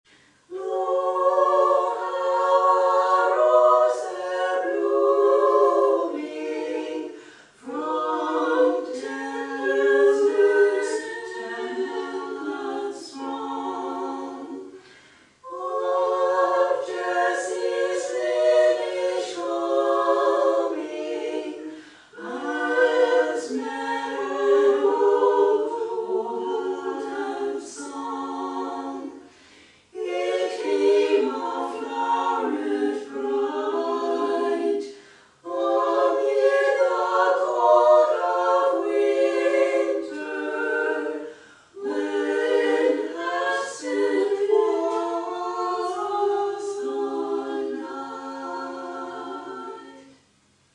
Medieval and Renaissance Carols
A selection of early carols in Latin, German and English presented by Canzonet in arrangements for three women's voices. In addition to singing, the members of Canzonet play Renaissance instruments including recorders, viola da gamba, flute, guitar and percussion The program features music from the 15th-century English Selden Manuscript and from the early 17th-century collections by the German composer/arranger, Michael Praetorius.